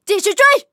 LT-35夜战语音.OGG